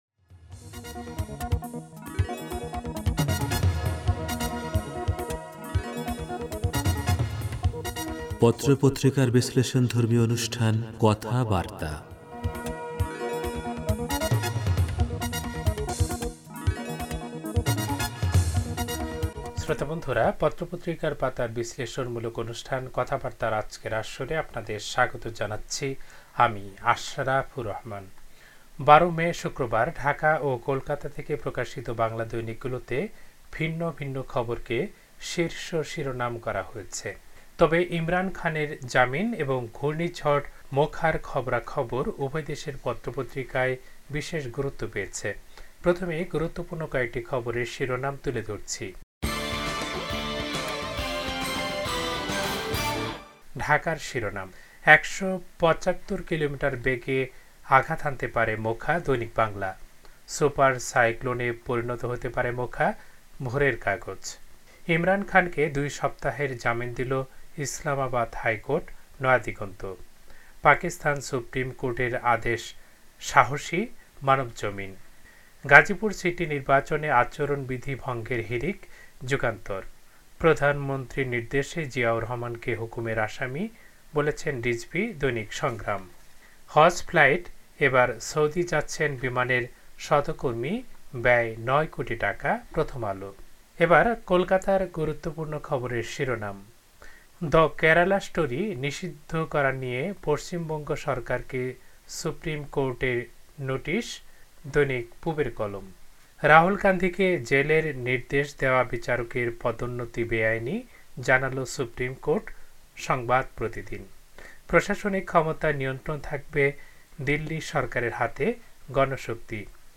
পত্রপত্রিকার পাতার অনুষ্ঠান কথাবার্তা